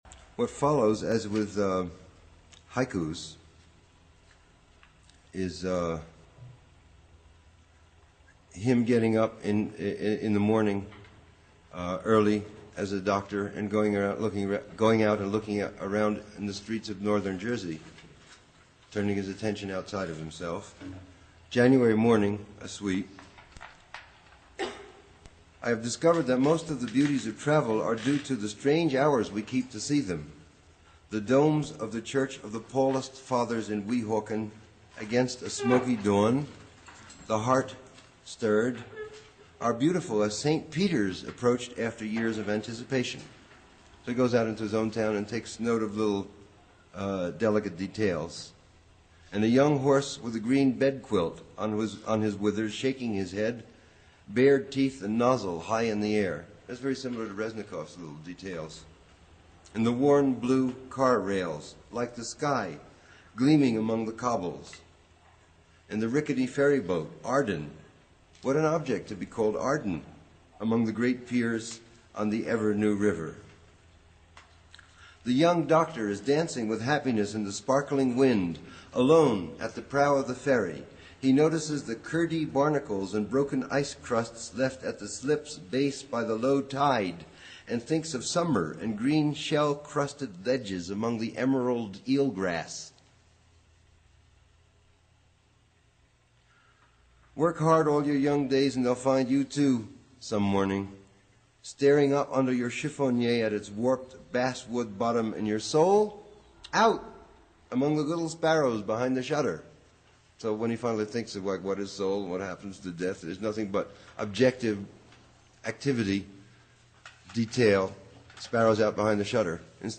Listen to Allen Ginsberg (4:16 min), whom Williams mentored, reading from and commenting on "January Morning."
allen_ginsberg_on_january_morning.mp3